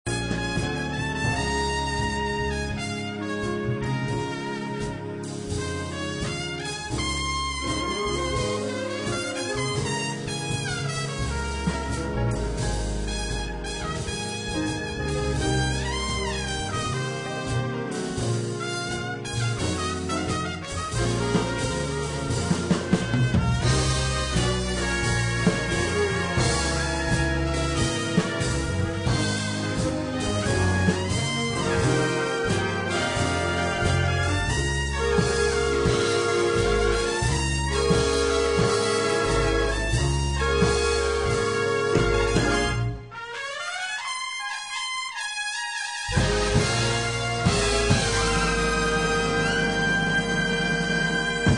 Trumpet Sounds of My Students: